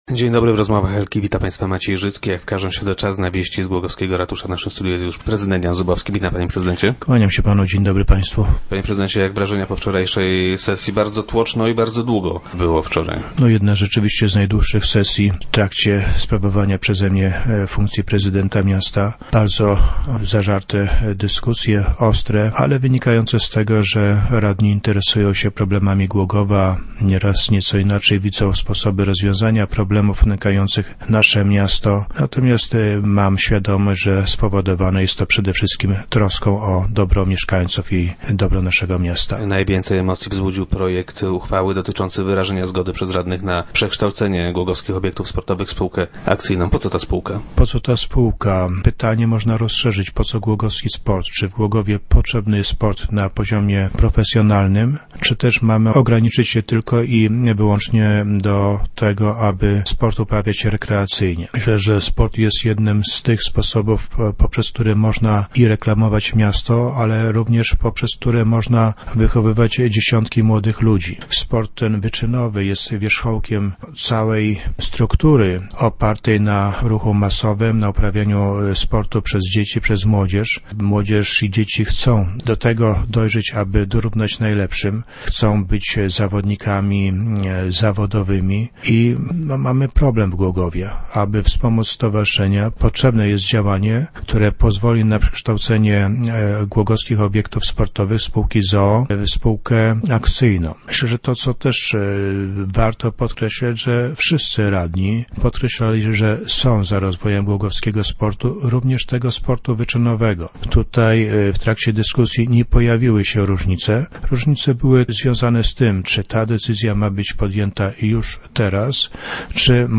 0202_zubowski.jpgRadni wyrazili zgodę na przekształcenie Głogowskich Obiektów Sportowych w spółkę akcyjną. - Pytanie o to, czy potrzebna jest taka spółka jest pytaniem o to, czy w Głogowie potrzebny jest sport - twierdzi prezydent Jan Zubowski, który był dziś gościem Rozmów Elki.